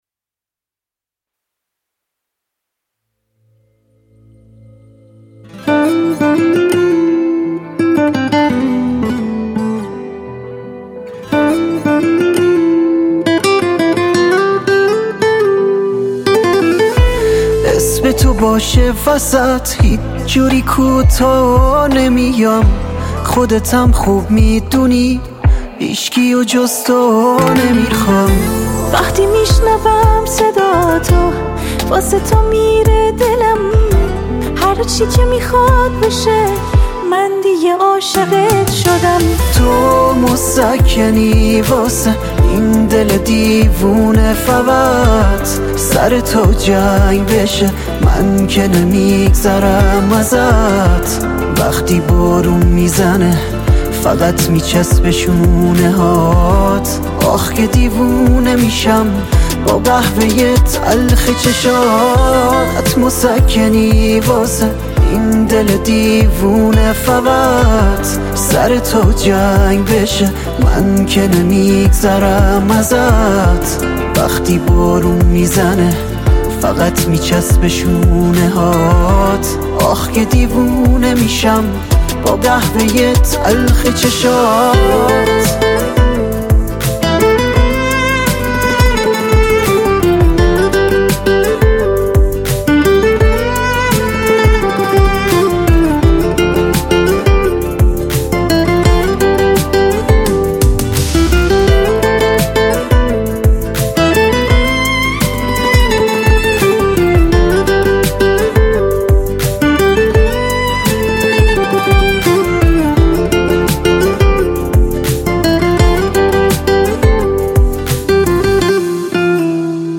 یک موزیک ولنتاینی جذاب